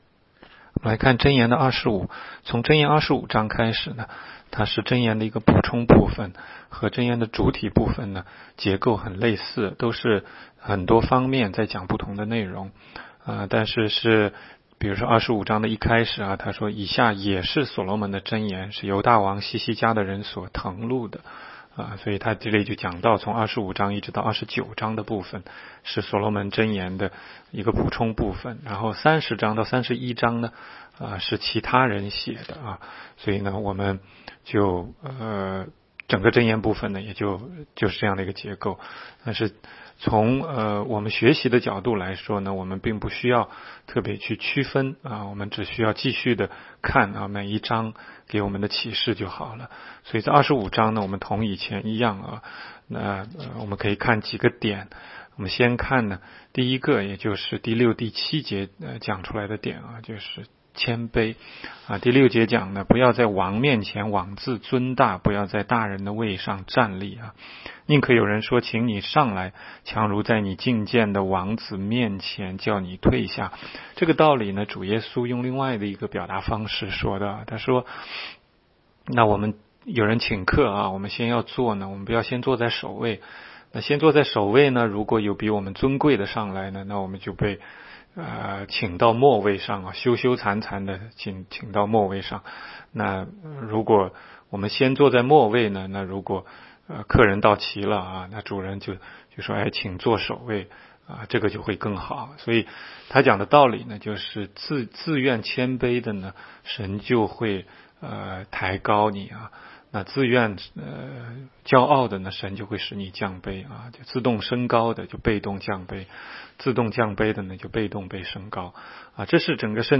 16街讲道录音 - 每日读经 -《 箴言》25章